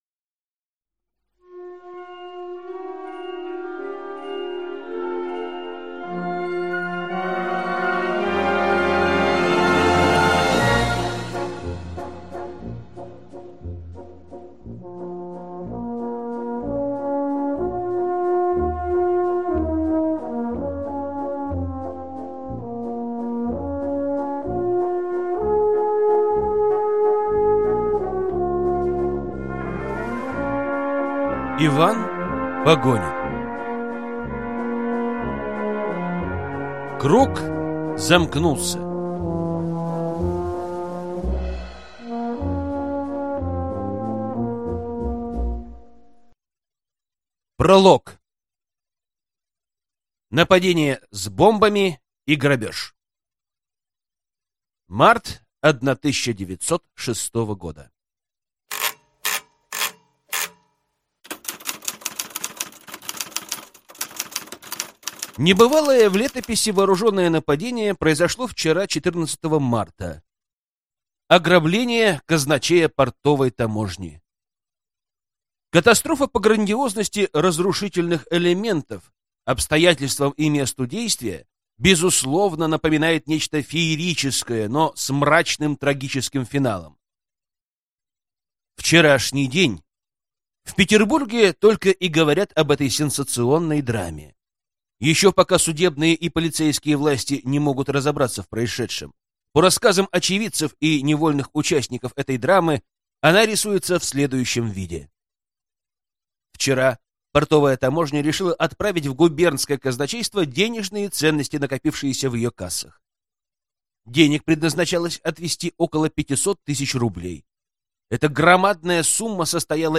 Аудиокнига Круг замкнулся | Библиотека аудиокниг
Прослушать и бесплатно скачать фрагмент аудиокниги